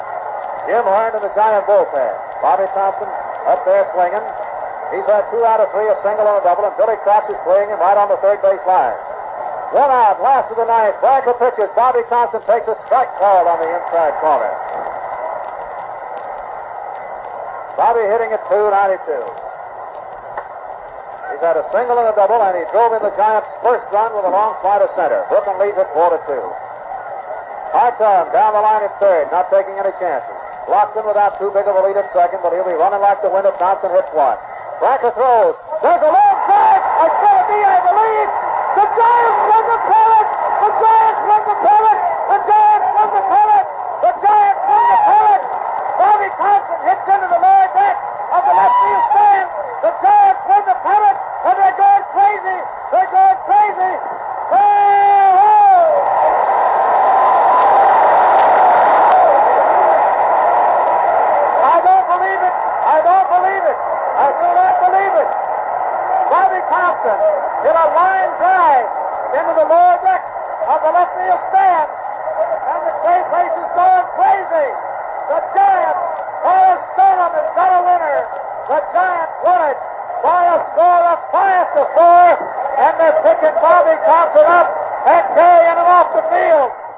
Russ Hodges’Call Bobby Thompson Polo Grounds 1951
Sounds of Baseball